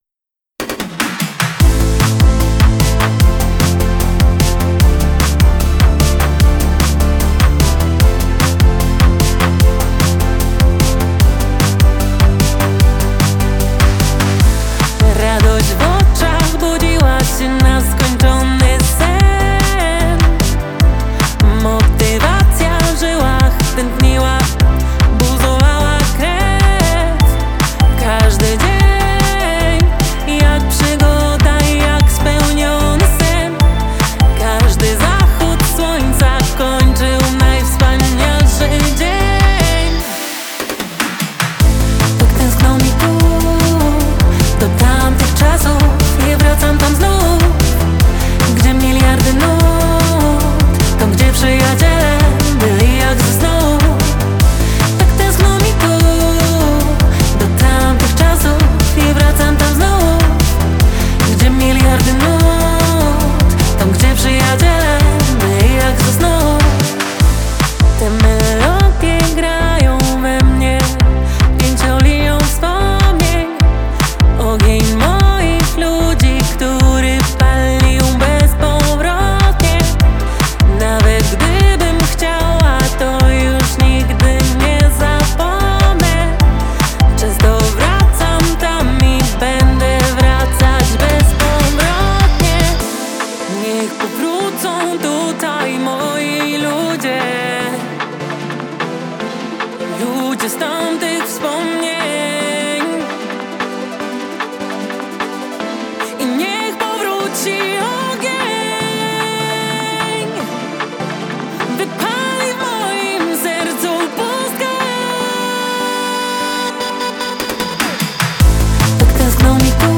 radiowy pop lekko klubowy vibe